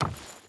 Wood Chain Run 5.wav